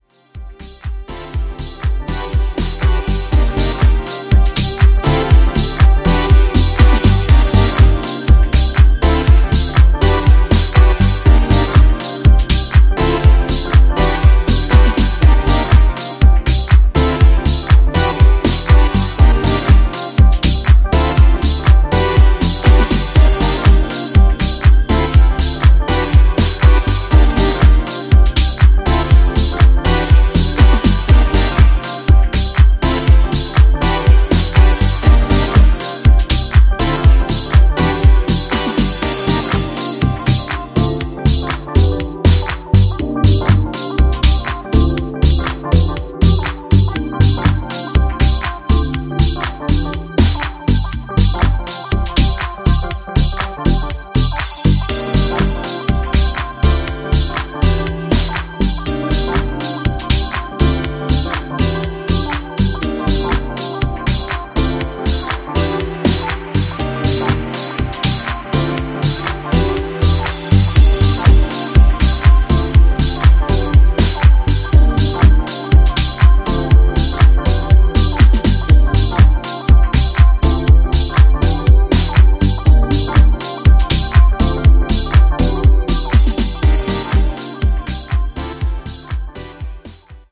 今回は、シカゴスタイルのディープハウスに仕上がったグッドな1枚です！
ジャンル(スタイル) DEEP HOUSE / HOUSE